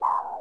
На этой странице собраны оригинальные звуки Windows 95: старт системы, уведомления, ошибки и другие знакомые мелодии.